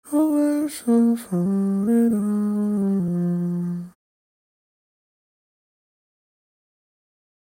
In dem folgenden Soundbeispiel hören Sie einen trockenen Gesang.
Vocals trocken:
Vocals-trocken-mit-autotune.mp3